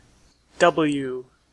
Ääntäminen
IPA : /ˈdʌb.l̩.juː/